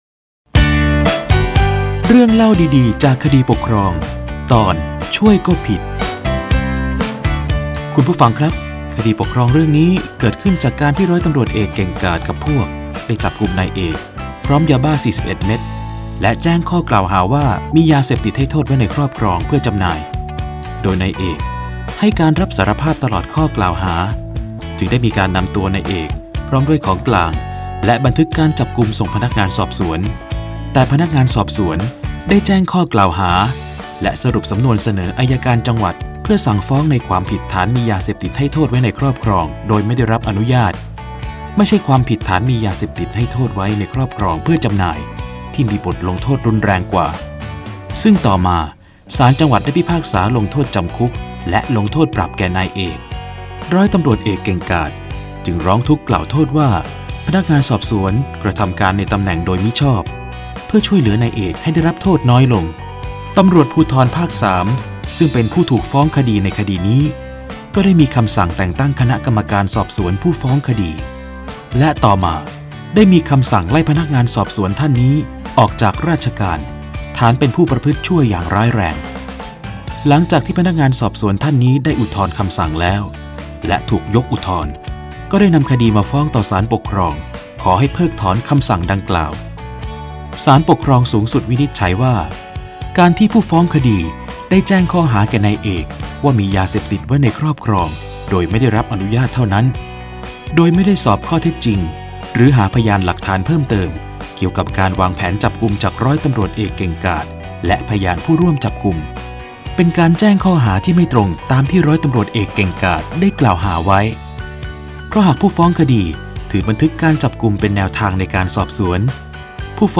สารคดีวิทยุ ชุด เรื่องเล่าดีดีจากคดีปกครอง - ช่วยก็ผิด